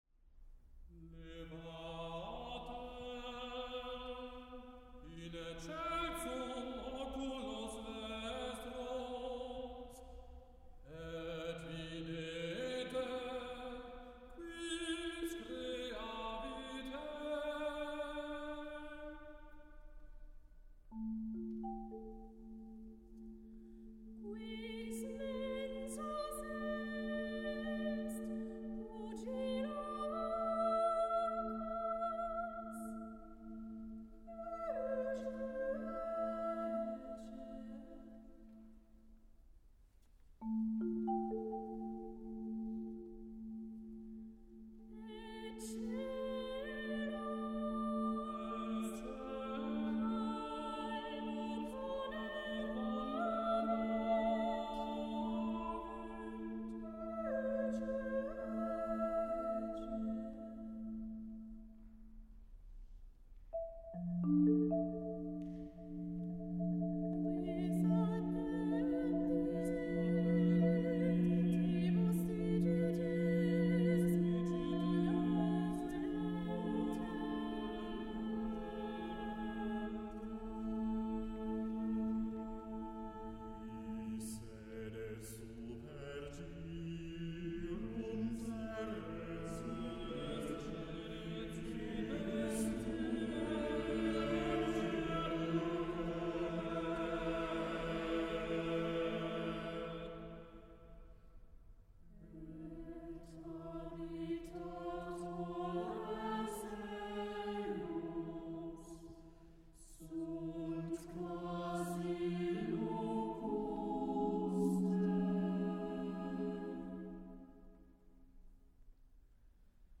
Live-Mitschnitte Konzerte 2023